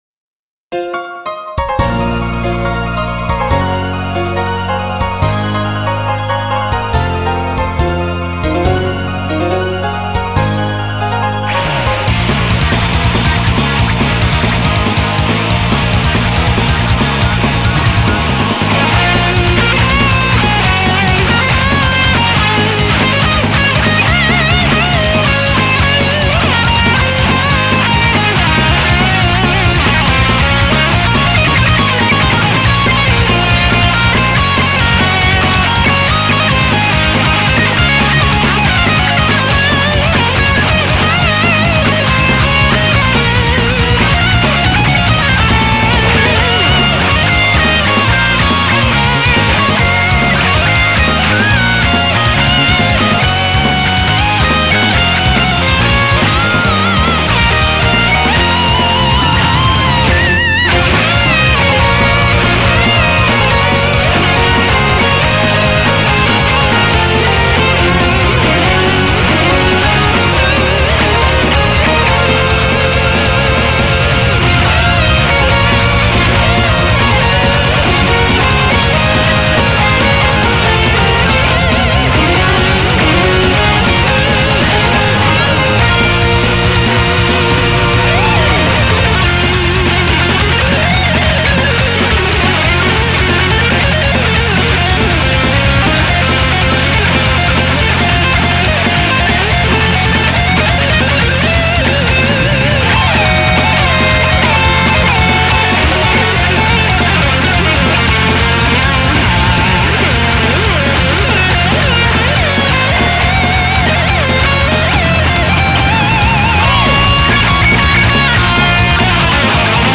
BPM140
Plays like a 10 for the most until the guitar solo.